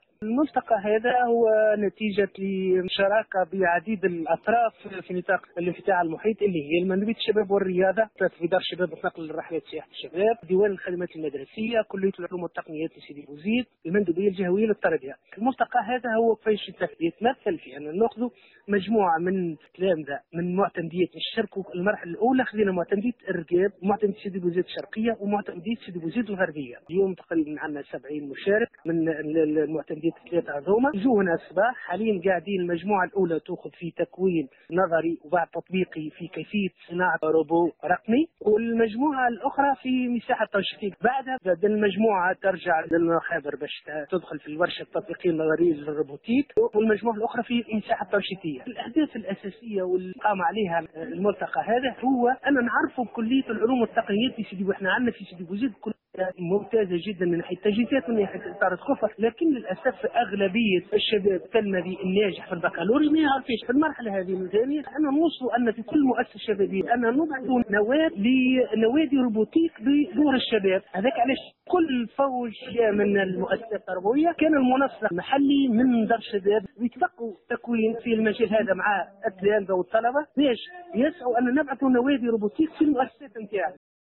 Sidi Bouzid: Lancement du forum régional des jeunes pour la robotique (Déclaration)